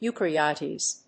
発音